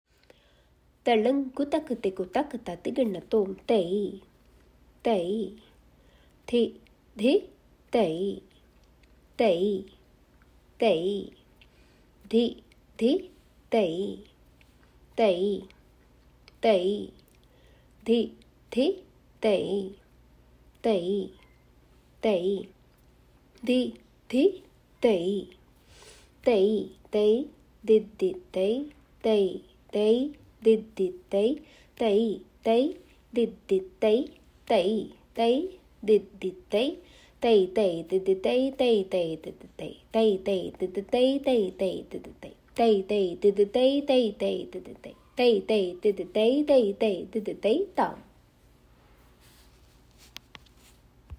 Following is the sollukettu (bols, syllables) for the third, eight and eleventh variation. The Bols are “Tai Tai Dhit Dhit Tai“.